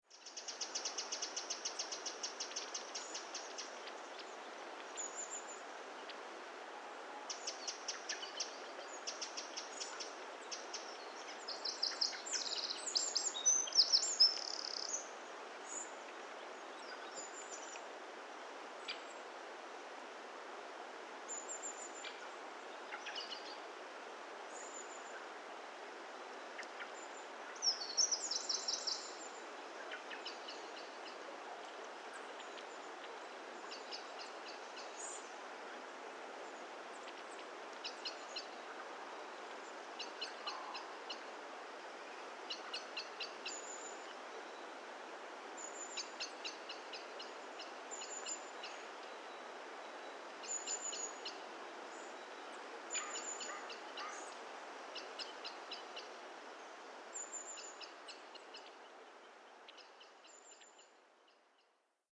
Tonos EFECTO DE SONIDO DE AMBIENTE de BOSQUE EN INVIERNO
Bosque_en_invierno.mp3